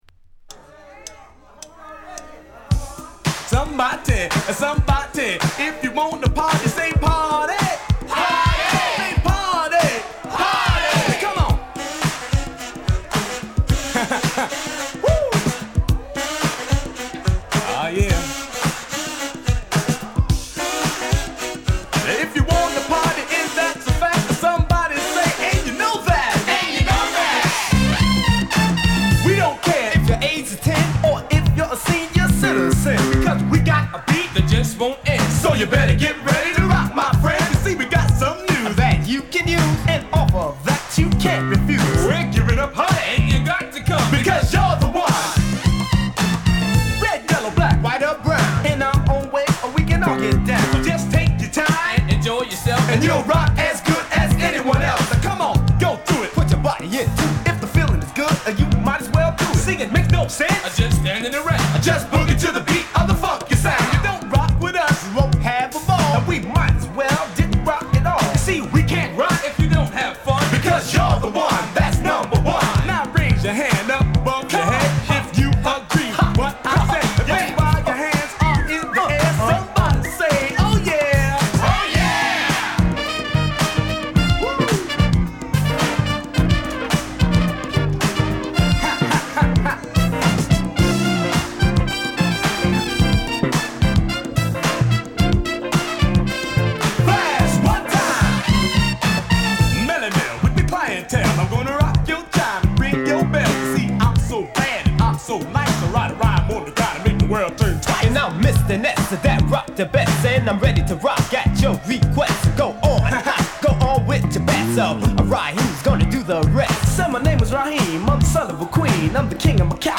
Home > Old School
5MC1DJのHip Hopグループ